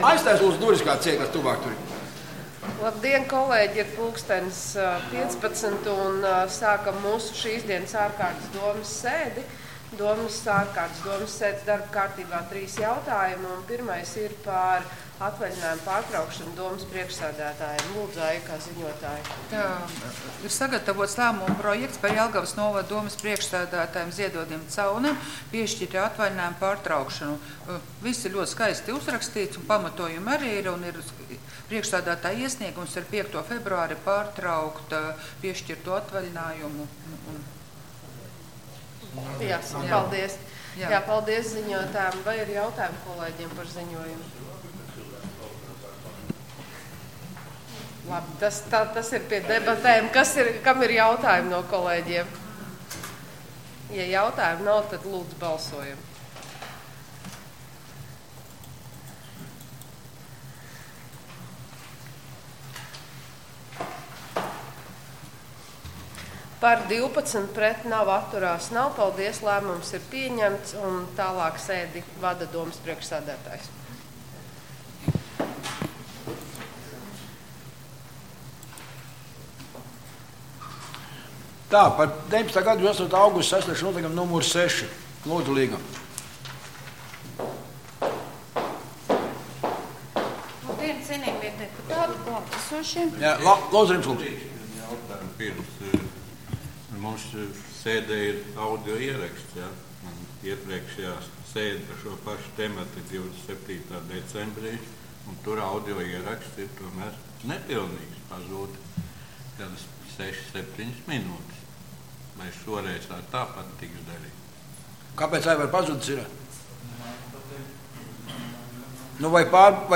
Domes ārkārtas sēde Nr. 3